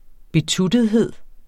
Udtale [ beˈtudəðˌheðˀ ]